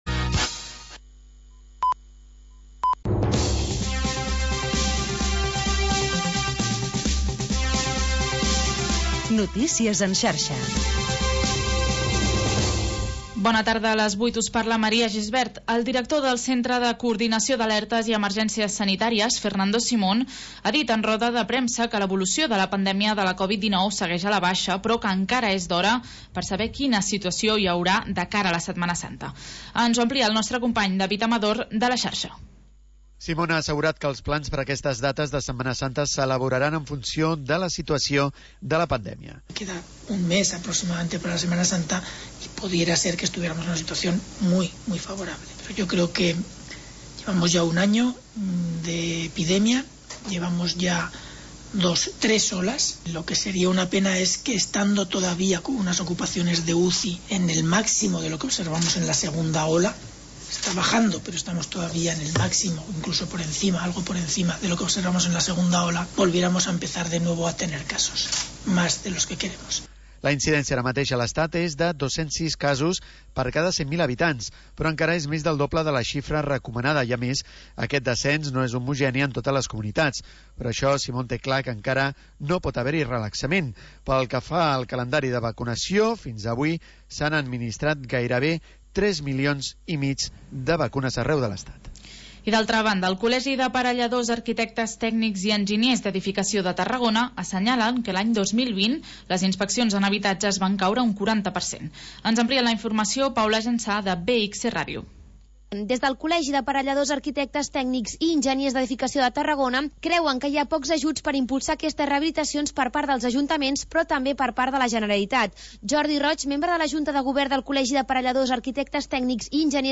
Programa de música independent